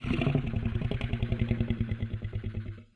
guardian_idle1.ogg